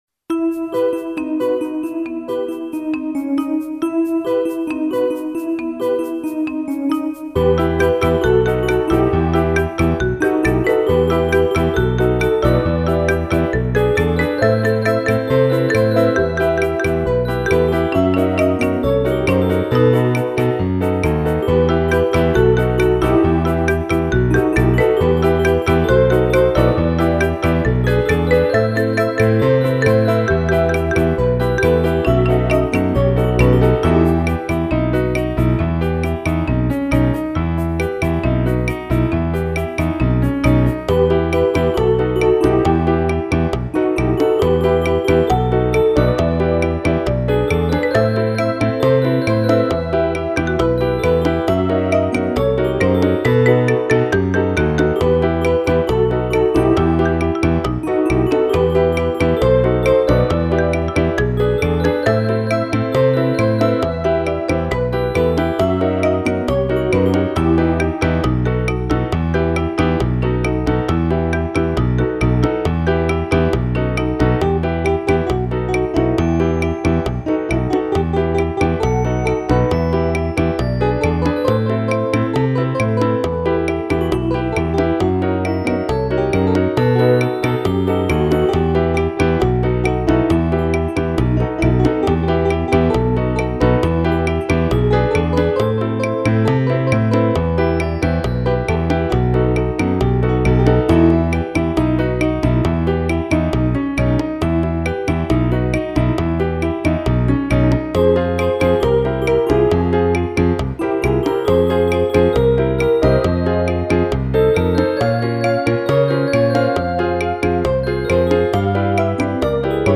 Version instrumentale :